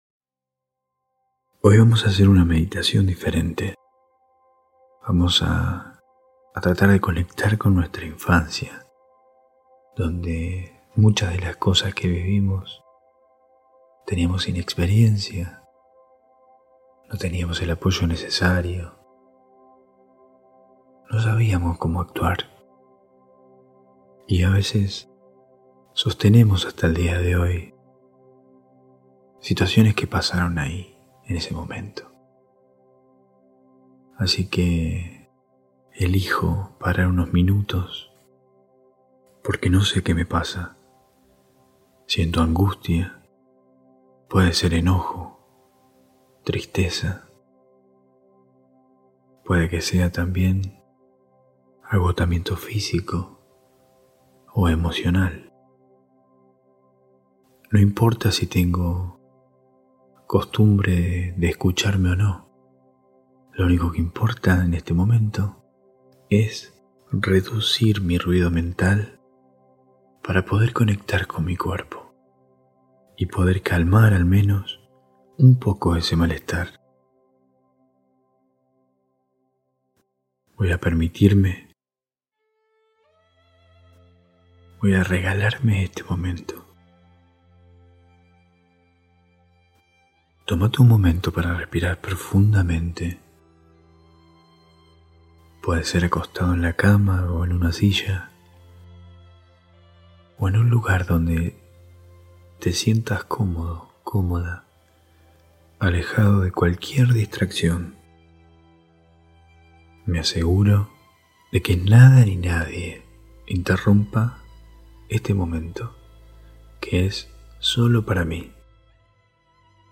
Meditación a la infancia